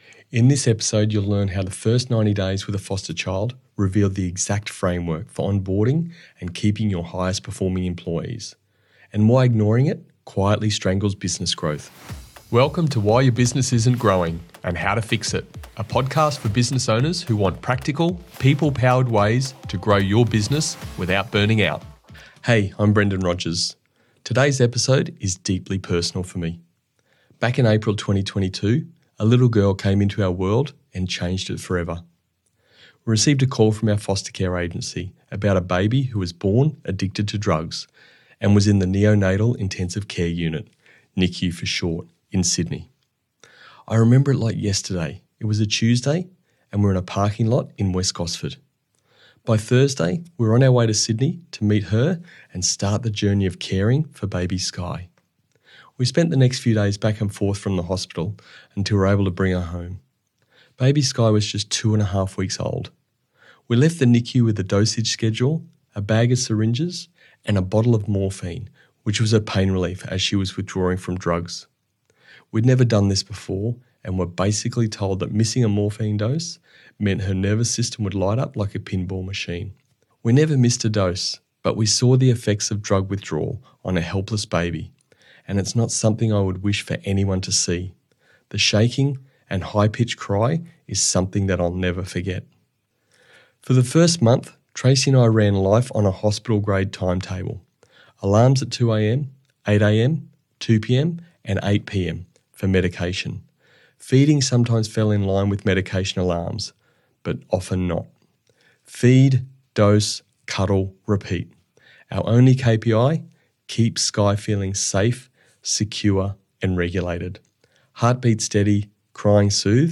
Expect simple scripts, performance-agreement templates, and Aussie straight talk that makes business growth feel doable.